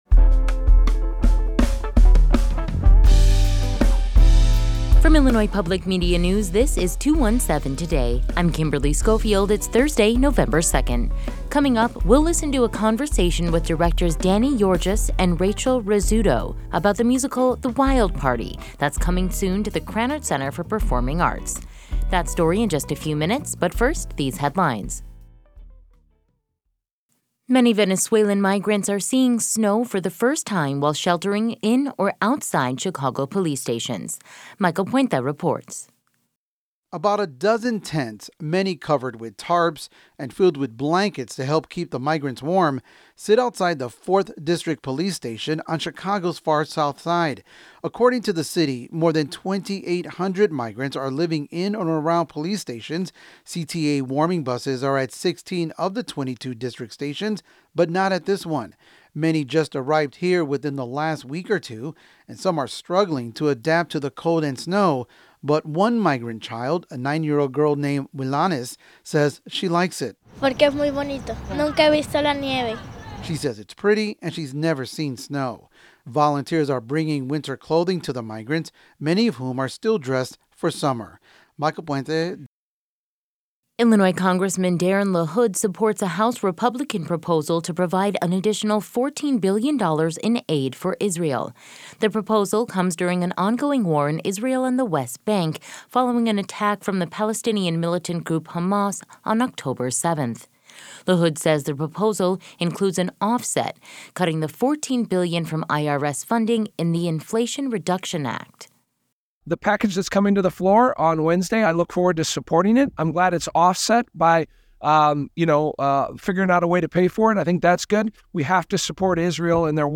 In today’s deep dive, we’ll listen to a conversation